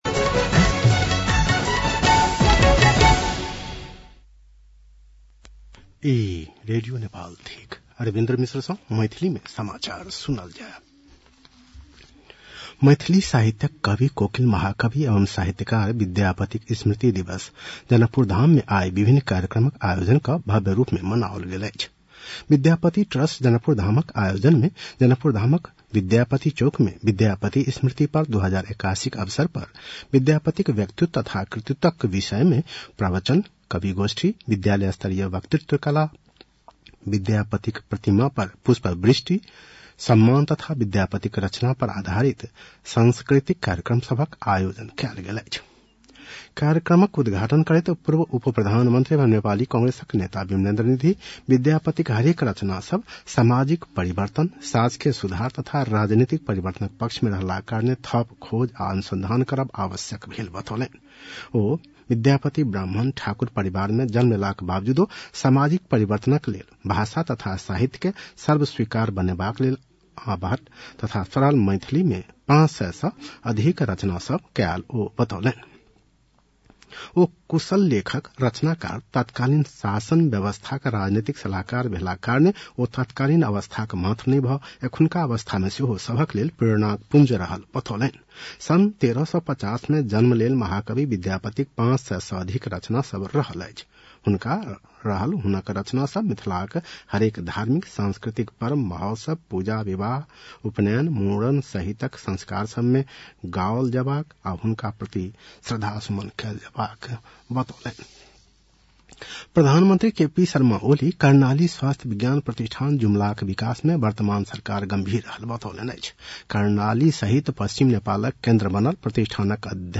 मैथिली भाषामा समाचार : २९ कार्तिक , २०८१
Maithali-news-7-28.mp3